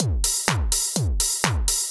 BK Beat_125.wav